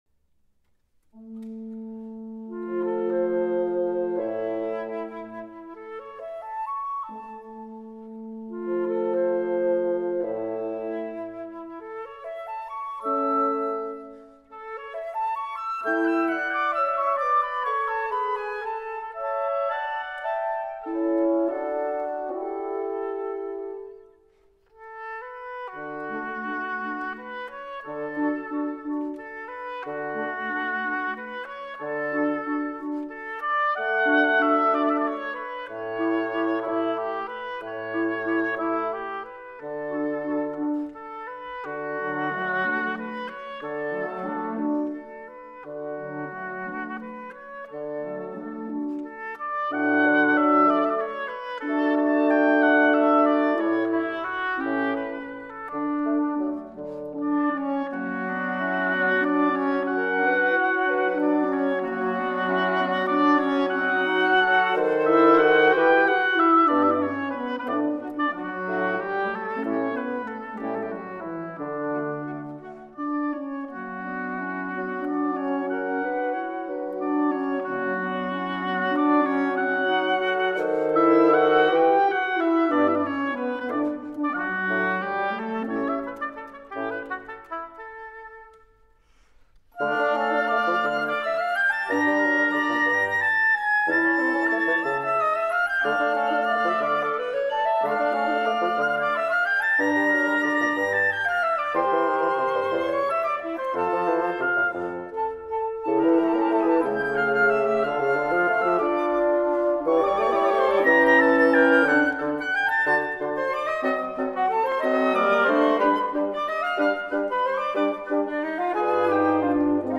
Soundbite 2nd Movt
For Flute, Oboe, Clarinet, Horn, and Bassoon